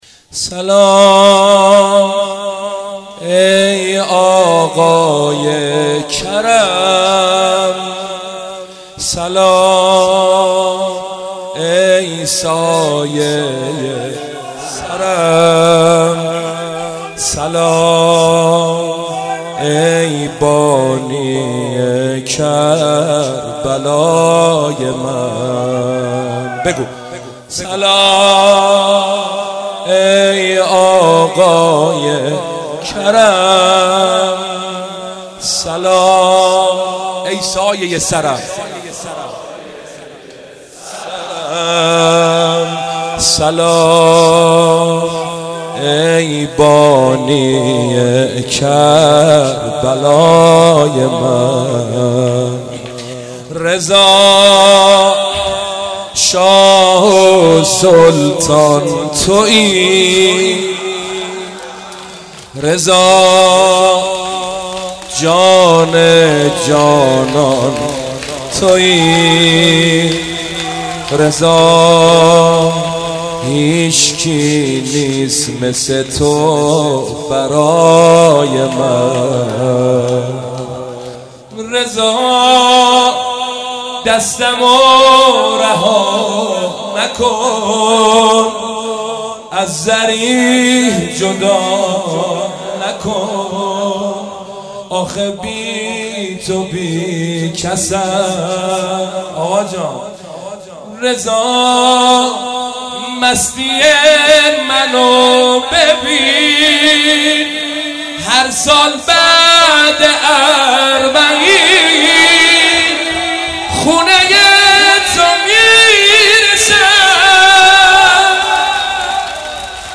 زمینه-شهادت امام رضا-سیب سرخی.mp3